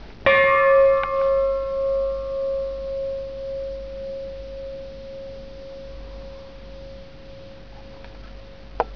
sein Klang: